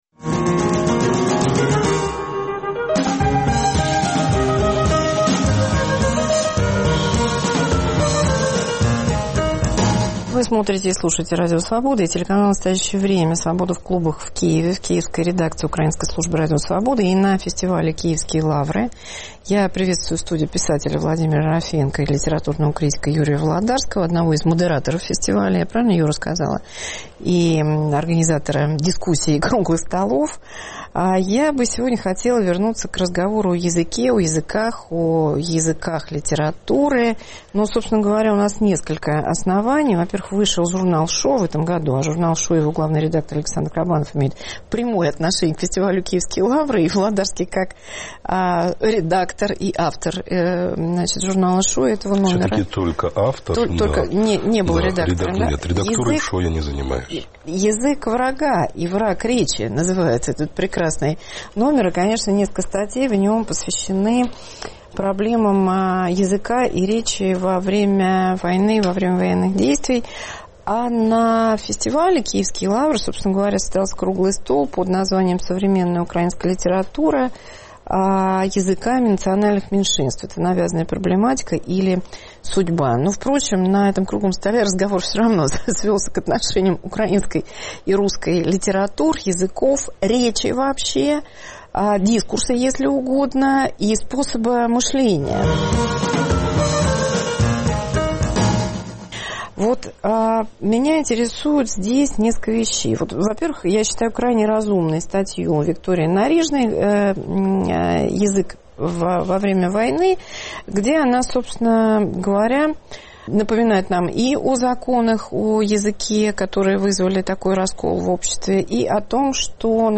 Тотальная украинизация или билингвизм? Разговор с писателями и критиками в Киеве.